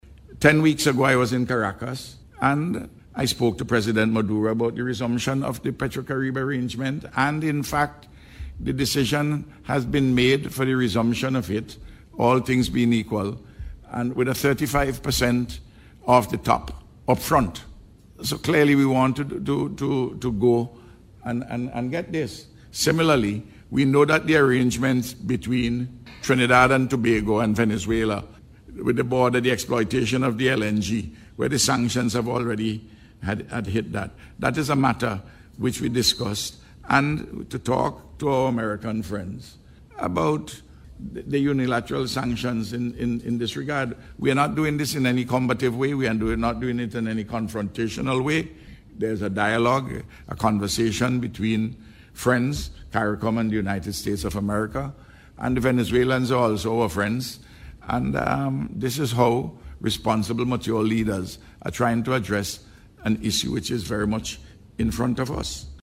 Prime Minister Gonsalves spoke on the issue, during the Closing News Conference, of CARICOM’s 43rd Regular Meeting of the Conference of the Heads of Government, hosted by Suriname.